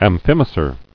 [am·phim·a·cer]